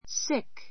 sick 中 A1 sík スィ ク